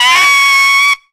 REV TRILL.wav